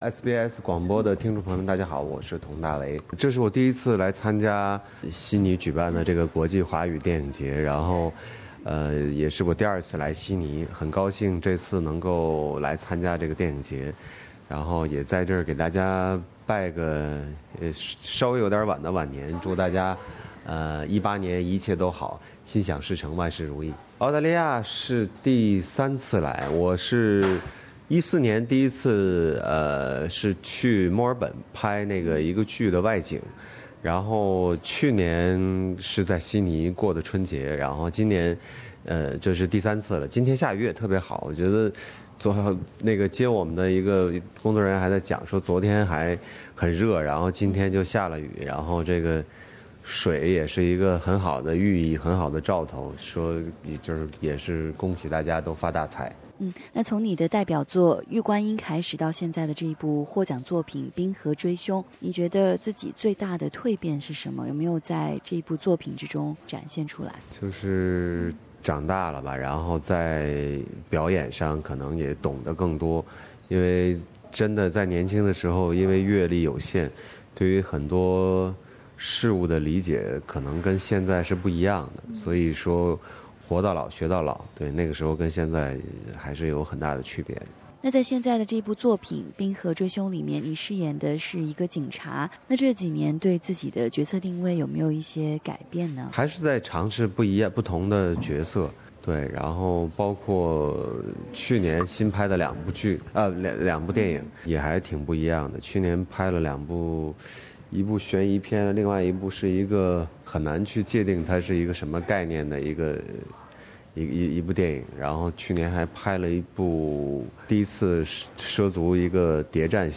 【SBS专访】华语电影节金龙奖最受欢迎男主角佟大为